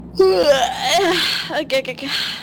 Yawn